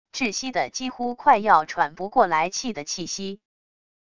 窒息的几乎快要喘不过来气的气息wav音频